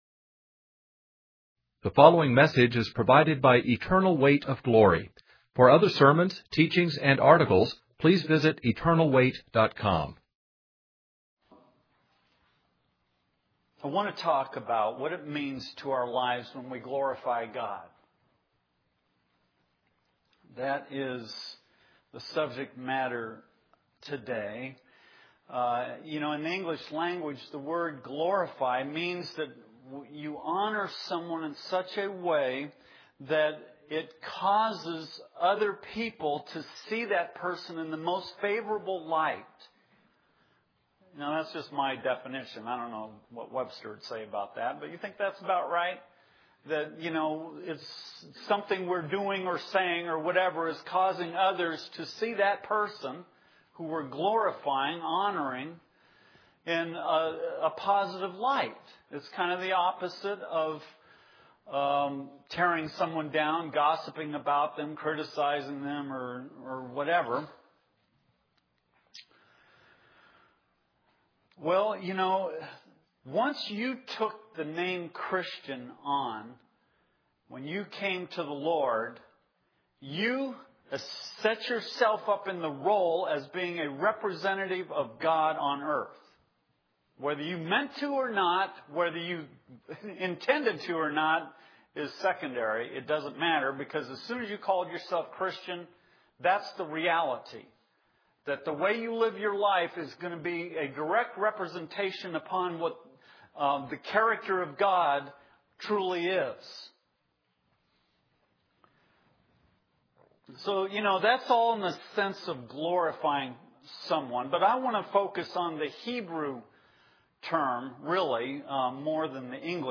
In this sermon, the preacher discusses the ways in which we try to exalt ourselves at the expense of others. He uses an illustration of two little boys at a parade who both desire to be part of a prestigious military academy.